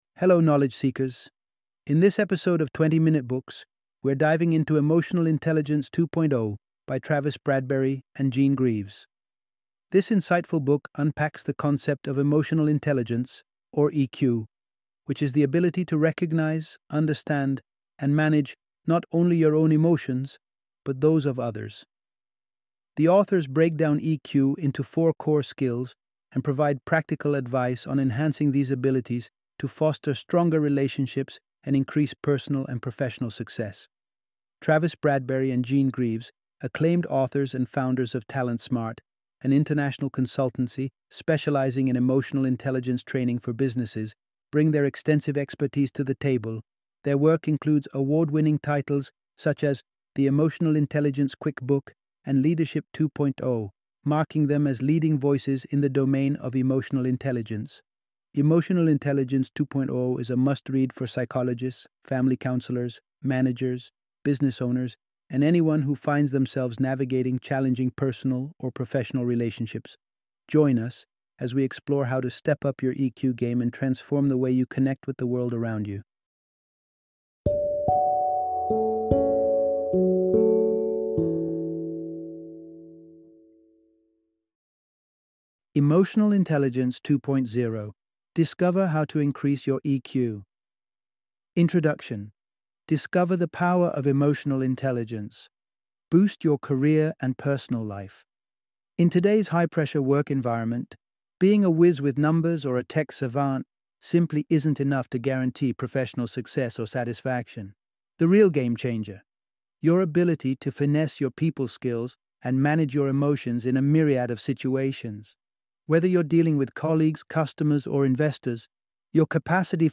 Emotional Intelligence 2.0 - Audiobook Summary